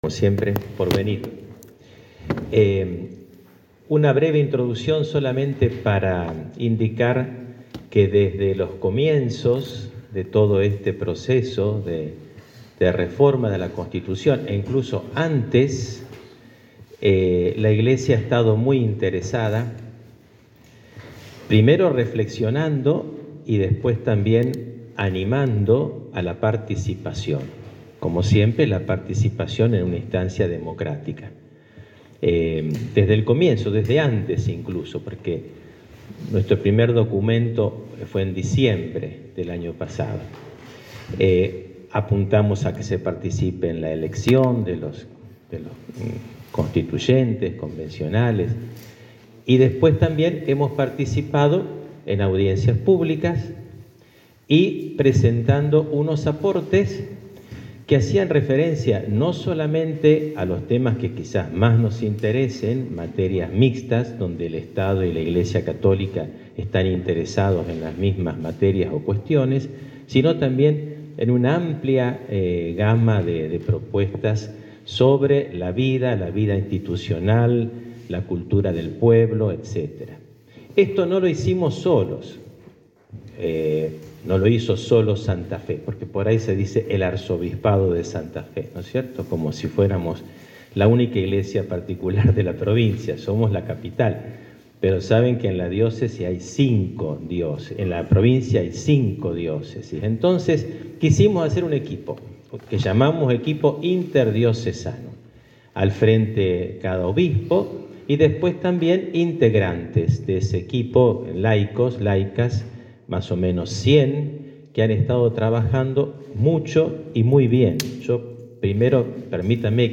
Conferencia del Arzobispo de Santa Fe, Sergio Fenoy